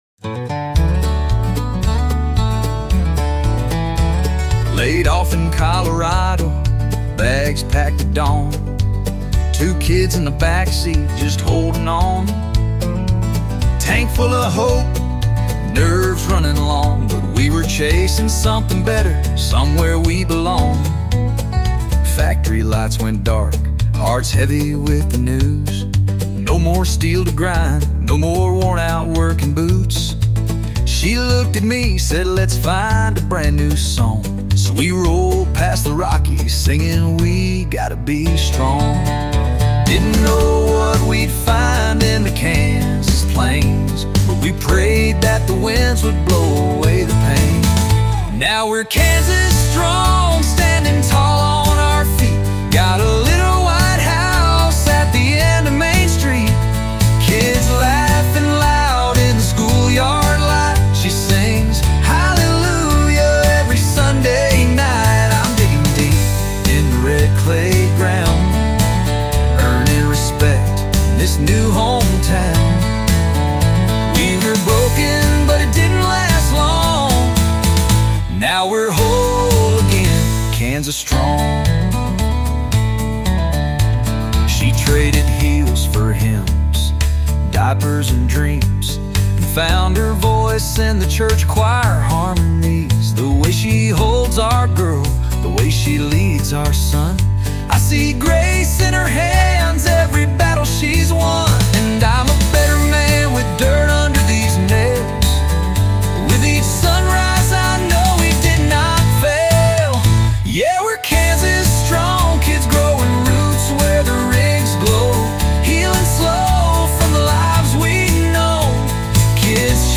heartfelt, narrative-driven song
Set to a driving country rock rhythm with uplifting slide guitar and layered harmonies, the song is a tribute to second chances, the strength of love, and the unshakeable spirit of a family that rebuilds itself from the ground up— Kansas strong .